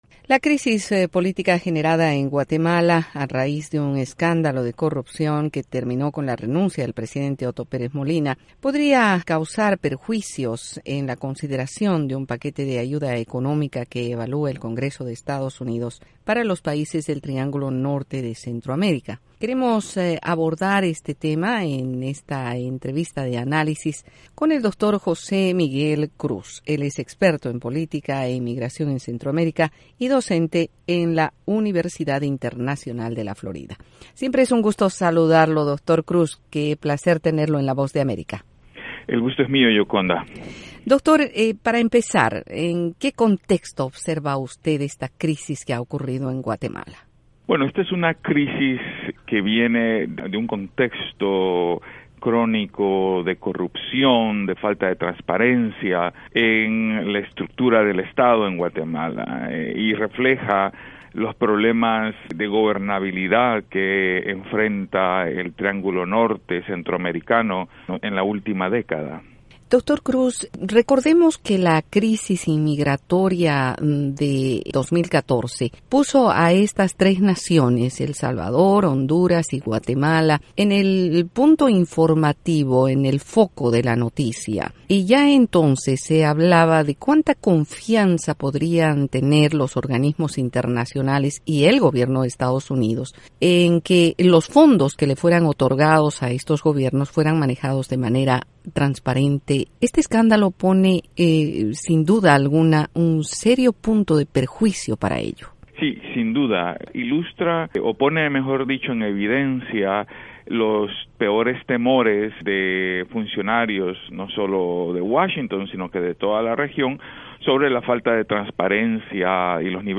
Entrevista con el experto en CENTAM